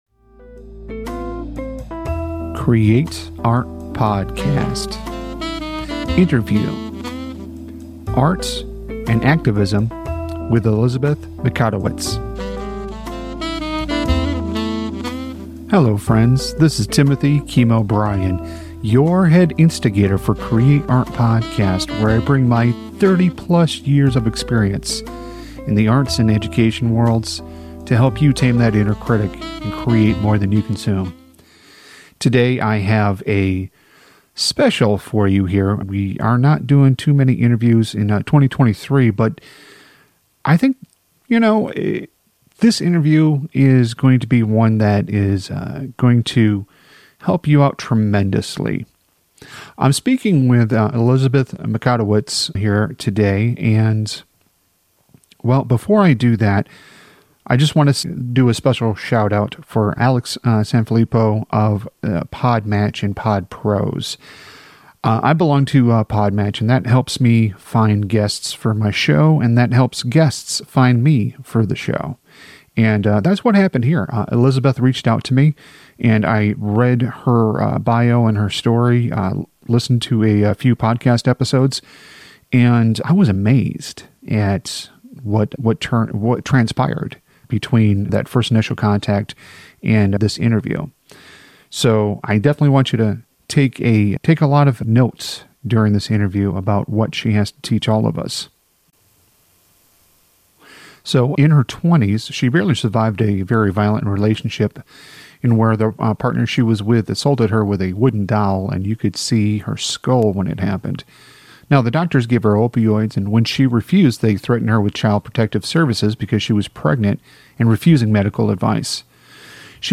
Art and Activism Interview